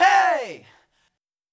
Track 11 - Vocal Hey 01.wav